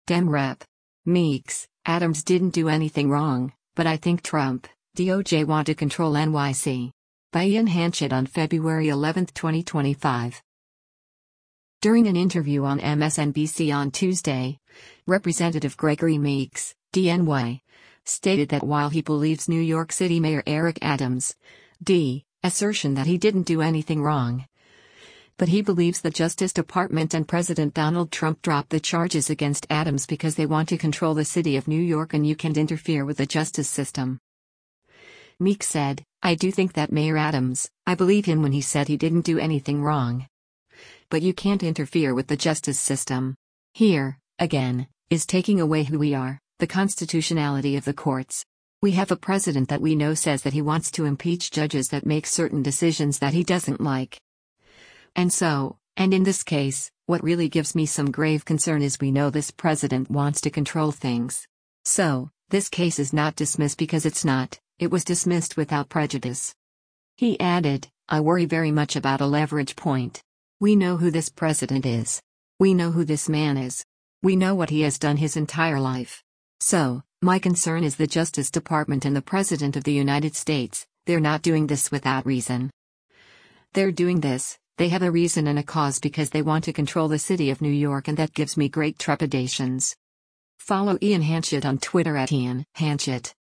During an interview on MSNBC on Tuesday, Rep. Gregory Meeks (D-NY) stated that while he believes New York City Mayor Eric Adams’ (D) assertion that he didn’t do anything wrong, but he believes the Justice Department and President Donald Trump dropped the charges against Adams because “they want to control the city of New York” and “you can’t interfere with the justice system.”